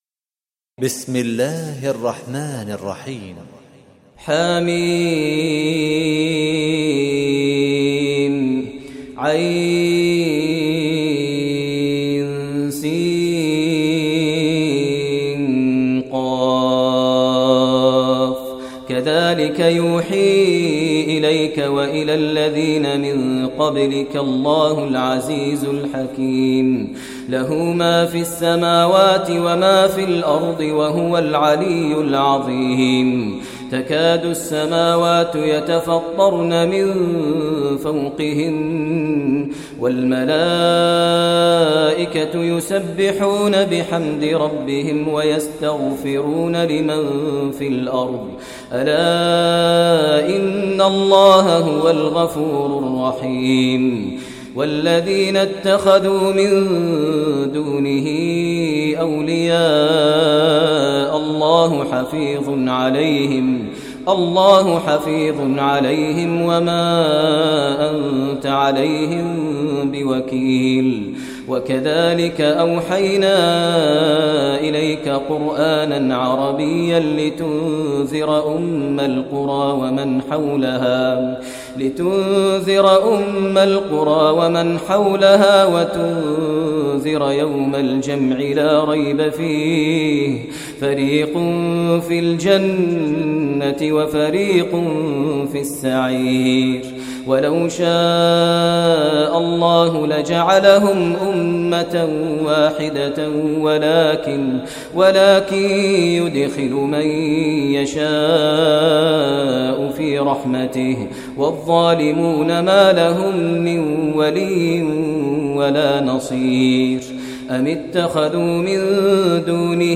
Surah Ash Shuara Recitation by Maher al Mueaqly
Surah Ash Shuara, listen online mp3 tilawat / recitation in the voice of Sheikh Maher Mueaqly.